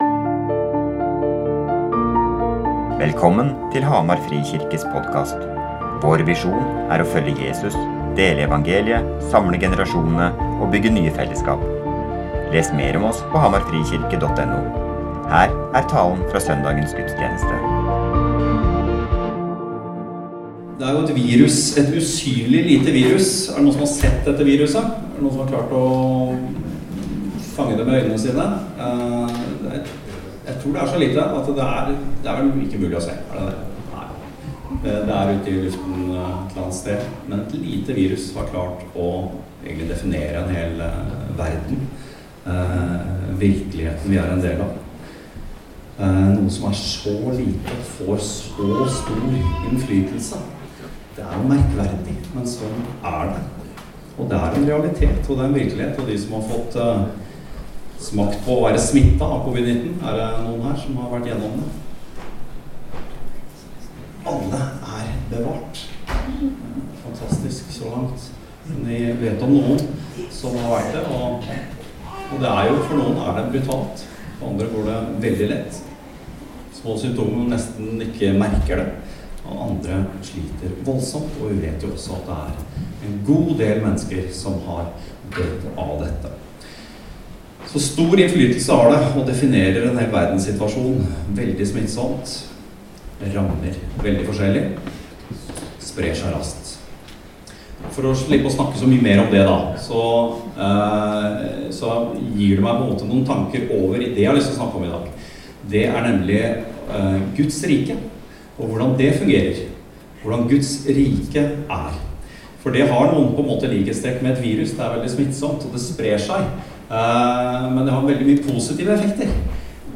Guds folk Gudstjenesten https